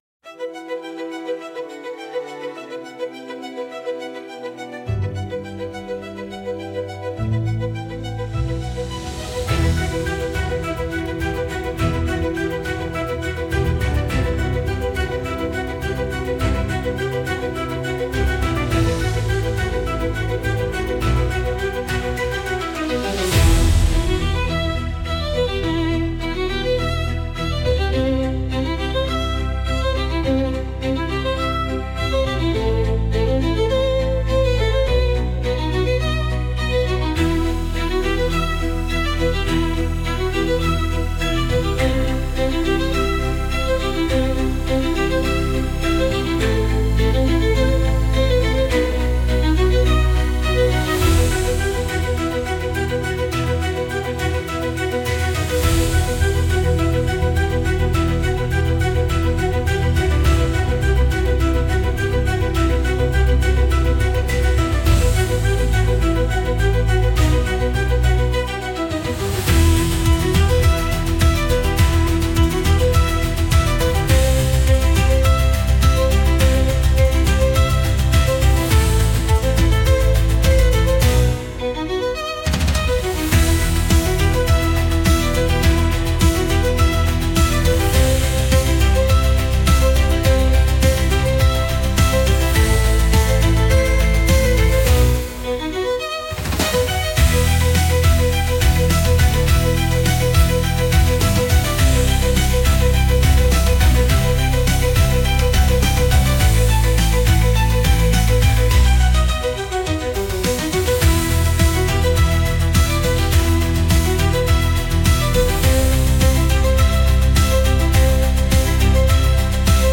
Instrumental / 歌なし
🎻🎼🔥 Violin Orchestra Style
バイオリンとオーケストラが織りなす、まさに新体操の王道とも言える正統派スタイル！
この曲の最大の特徴は、緻密に計算されたビルドアップです。
最初はシンプルな旋律から始まり、徐々に楽器の音が重なり、後半に向けてどんどん音圧と激しさが増していきます。